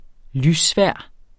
Udtale [ ˈlys- ]